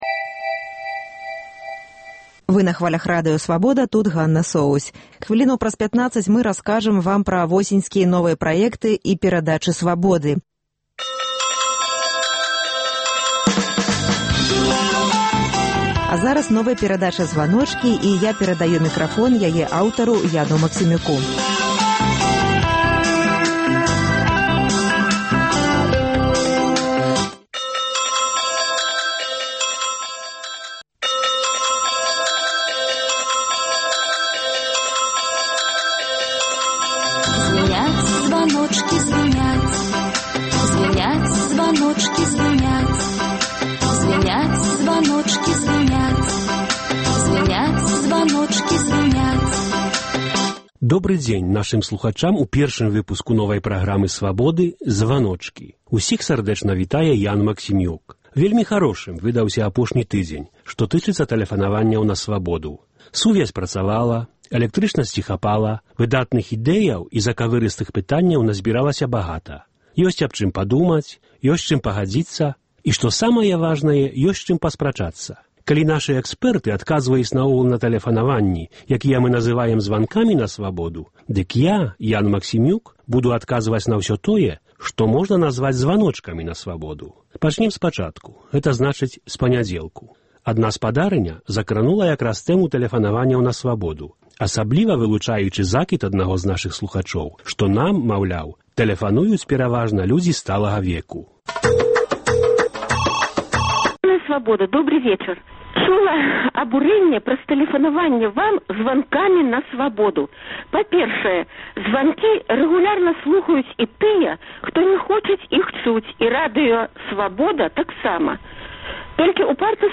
Гэта перадача — яшчэ адна магчымасьць для нашай рэдакцыі пагутарыць з нашымі слухачамі, уступіць зь імі ў адмысловую форму дыялёгу. “Званочкі” — гэта дыялёг з тымі слухачамі, якія звоняць на наш аўтаадказьнік у Менску і пакідаюць там свае думкі, прапановы і заўвагі.